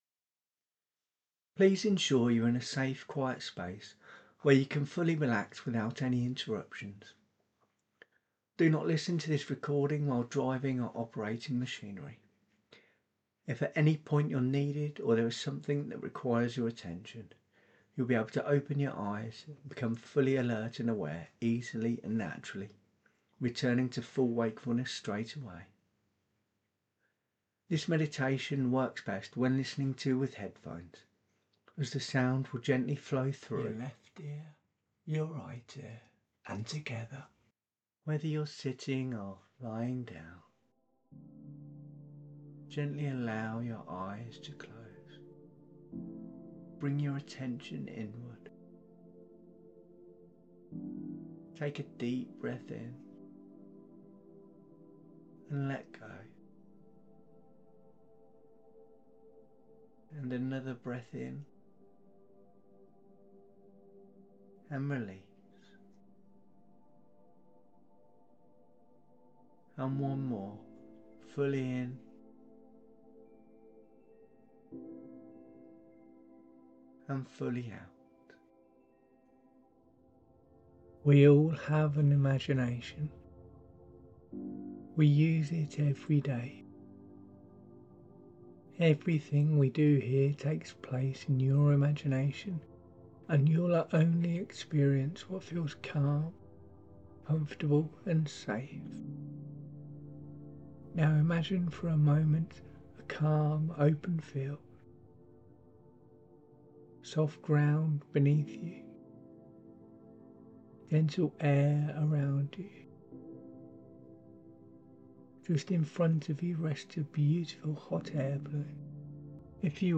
Free Hypnosis Meditation MP3
This dual (split) recording gently engages both sides of your mind, helping you experience a deeper sense of balance and relaxation.